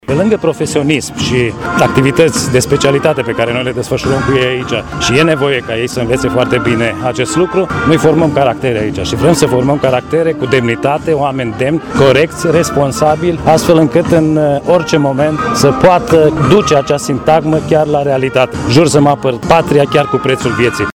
Generalul Vasile Bucinschi, comandantul Academiei Forţelor Aeriene Henri Coandă Braşov ne spune ce înseamnă pentru cariera militară şi pentru studenţii boboci, această ceremonie de depunere a jurământului: